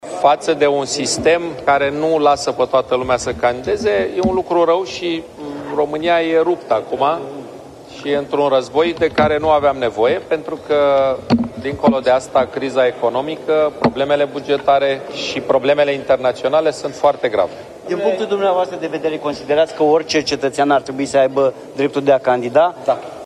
Orice cetățean ar trebui să aibă dreptul de a candida, spune fostul premier Victor Ponta în scandalul interzicerii candidaturii lui Călin Georgescu de către Biroul Electoral Central.